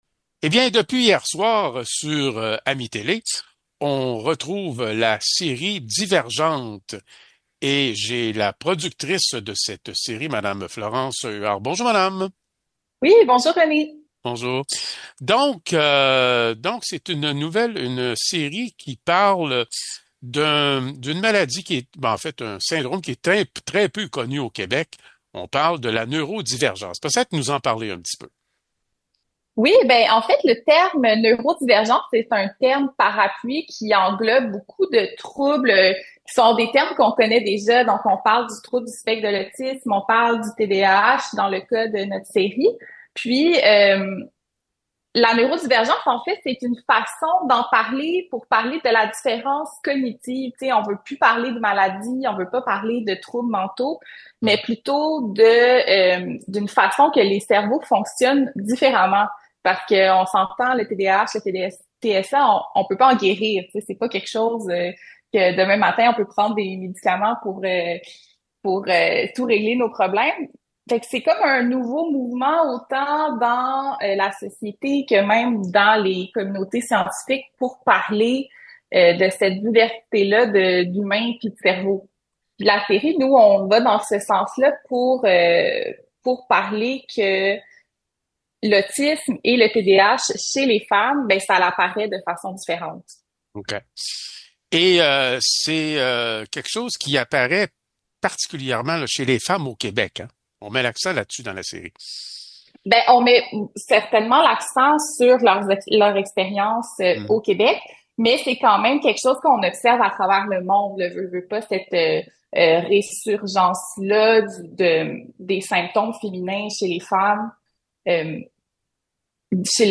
Nous continuons nos entrevues avec les artisans d’AMI-Télé.